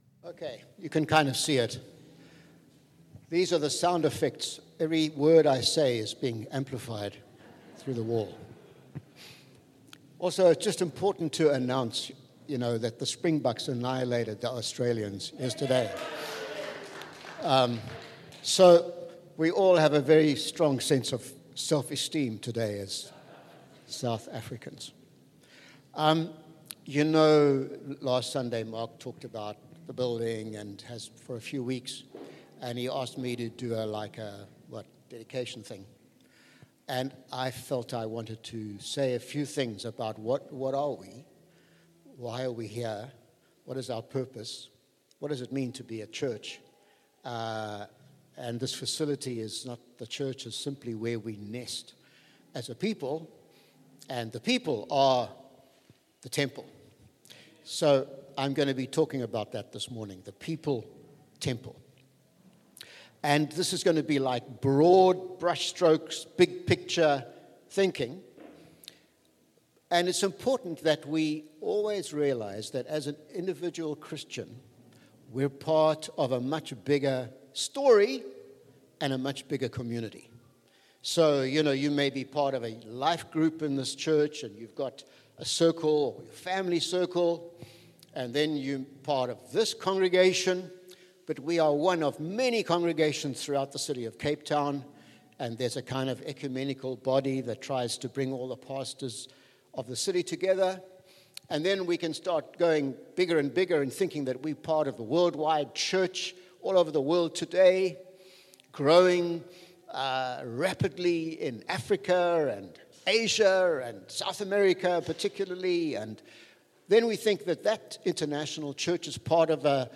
Sunday Service – 11 August
Sermons